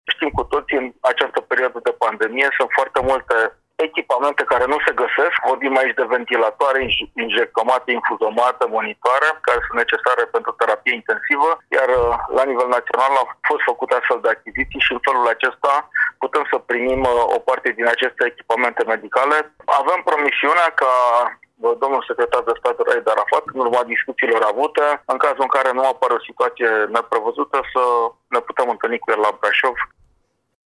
Anunțul a fost făcut de președintele Consiliului Județean Brașov, Adrian Veștea: